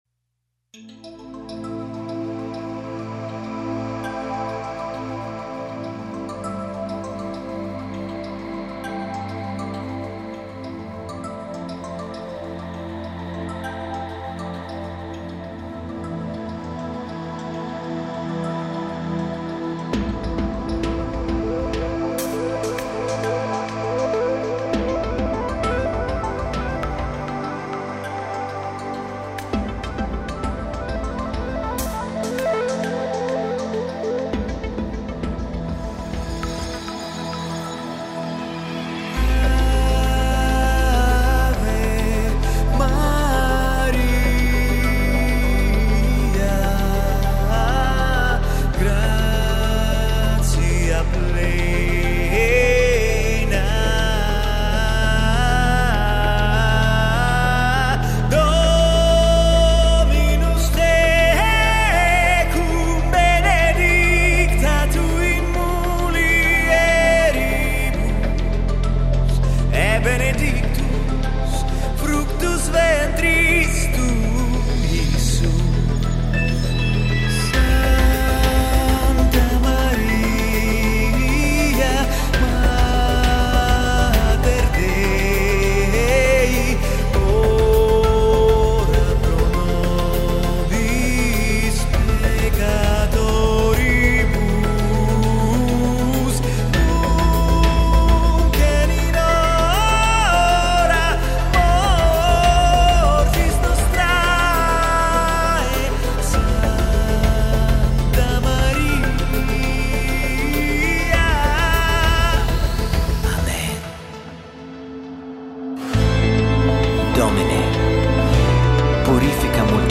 В общем, попробовал написать красивую и возвышенную мелодию для вокала и современной электроники. Вокальная партия и микс находится в работе, возможны рабочие изменения.